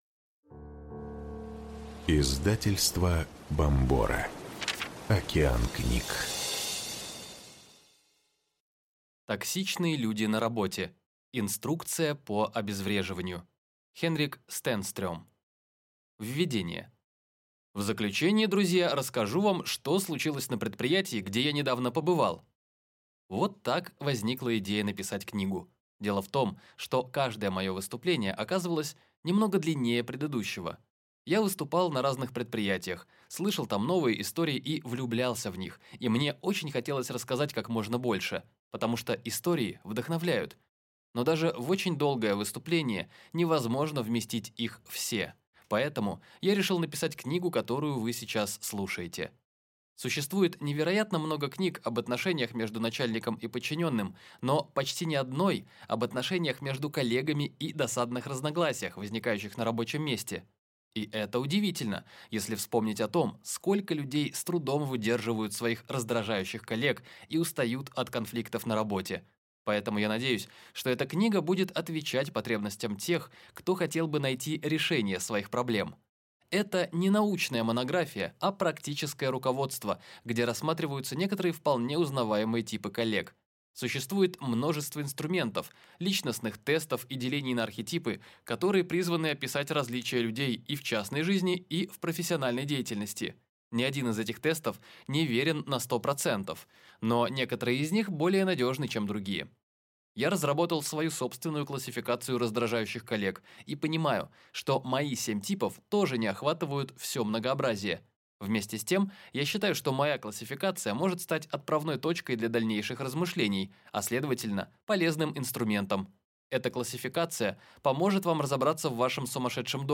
Аудиокнига Токсичные люди на работе. Инструкция по обезвреживанию | Библиотека аудиокниг